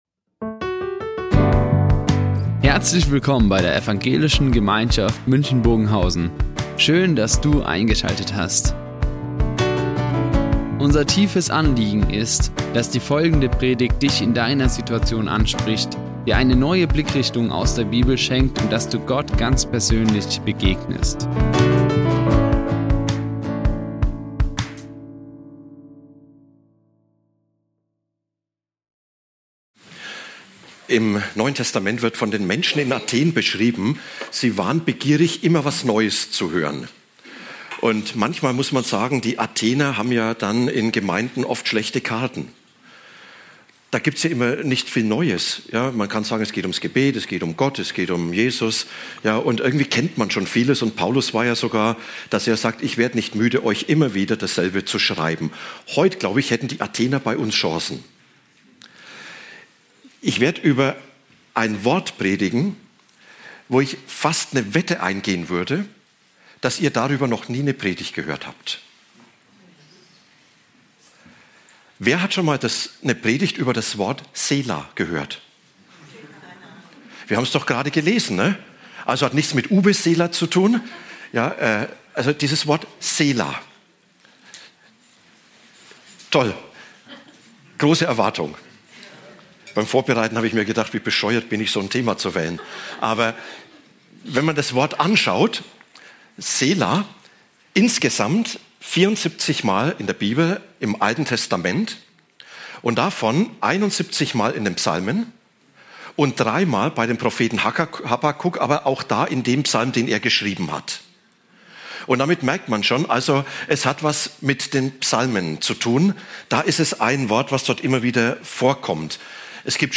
Eine Predigt
Die Aufzeichnung erfolgte im Rahmen eines Livestreams.